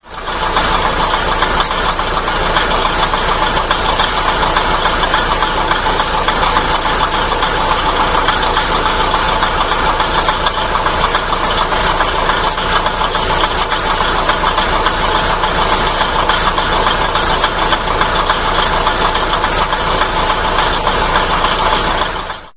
仙崎駅に停車中の636D・キハ234のアイドリング音です。
キハ23は、DMH17エンジンを搭載した最後の新製車です。かつては、日本全国いつでも、どこでも聞けた懐かしい音ですが、ちかごろは耳にする機会も少なくなりました。
キハ234には冷房装置がなく、それ故非常にクリアに収録できました。